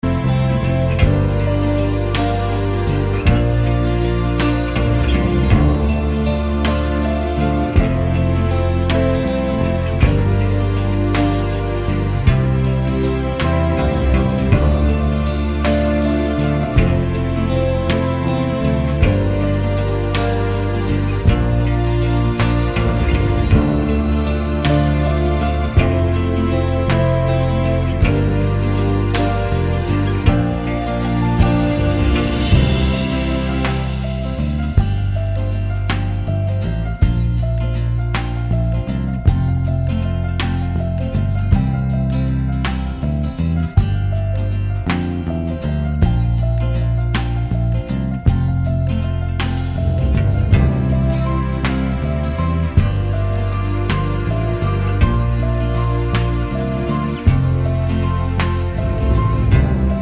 Down 3 Semitones Duets 3:14 Buy £1.50